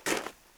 Sounds / Foot Steps / Snow
snowFootstep03.wav